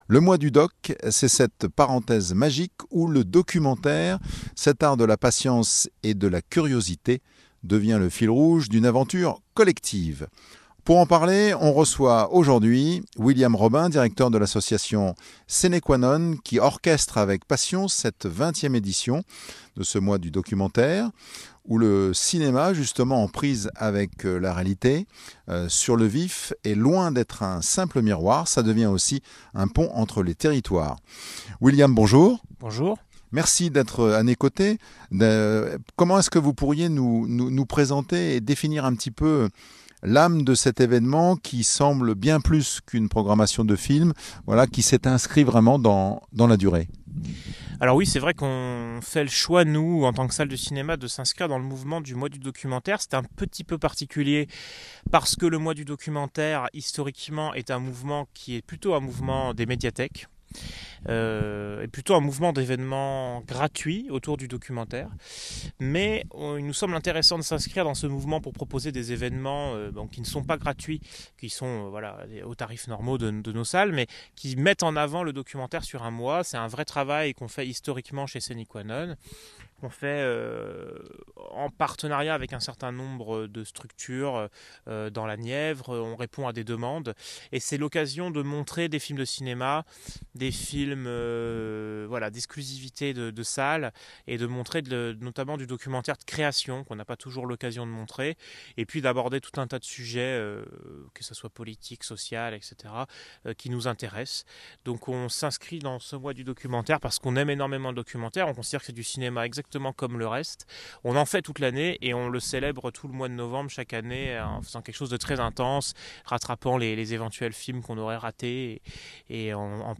%%Les podcasts, interviews, critiques, chroniques de la RADIO DU CINEMA%% La Radio du Cinéma – Musiques & répliques cultes 24/7 le Mois du documentaire: une fabrique d’images justes et pas juste d'images, c'est juste ?